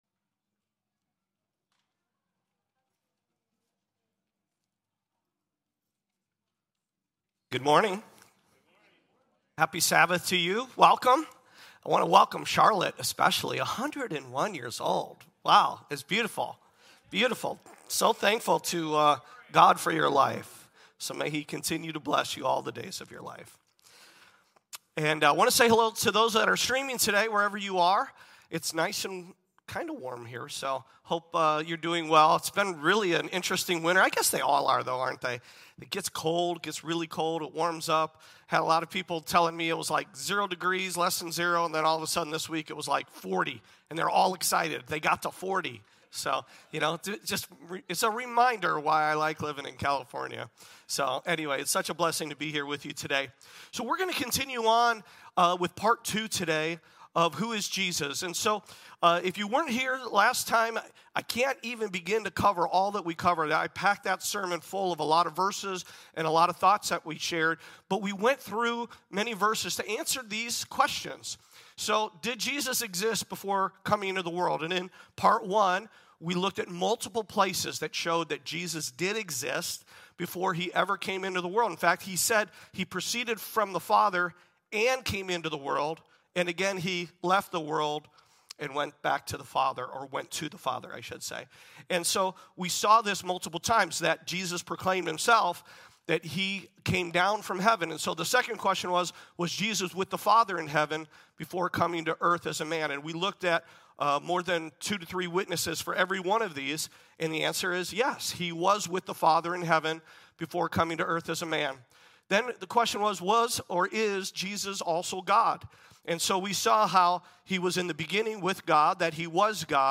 In this teaching